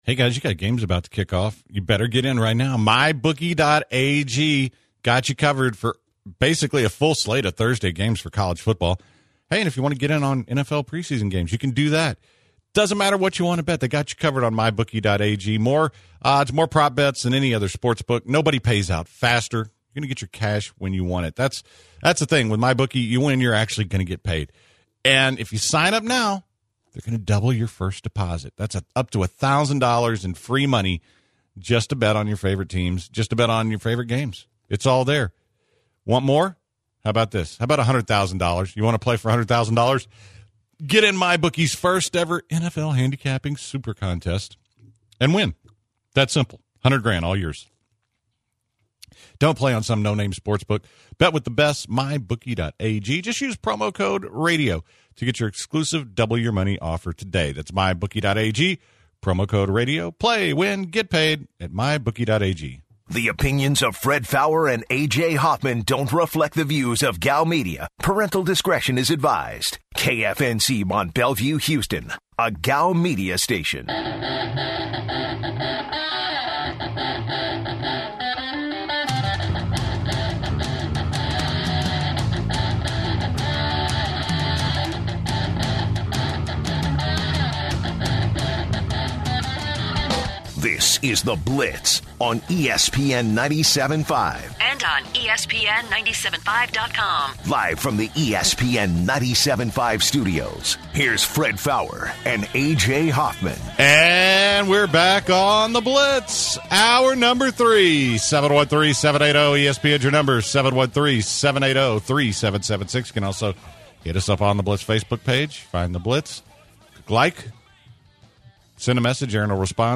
The guys launch hour number three discussing this weekend’s college football matchups